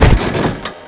suspension
compress_truck_4.wav